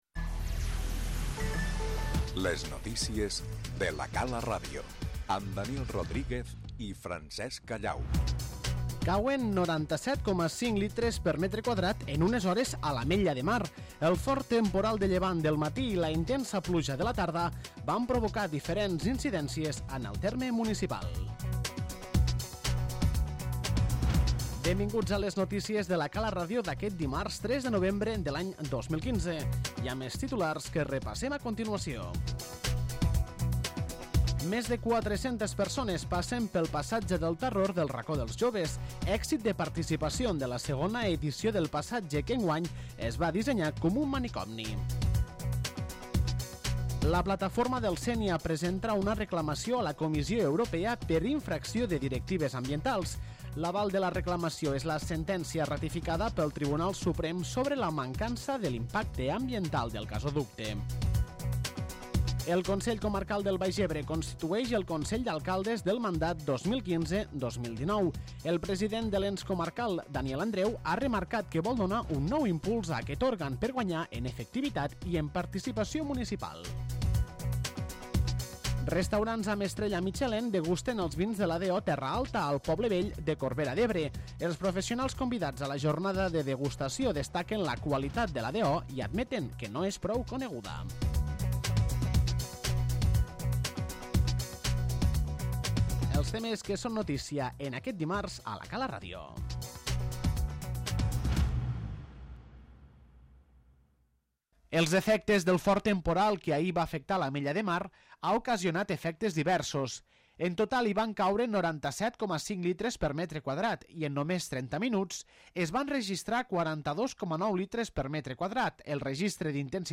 Les notícies 03/11/2015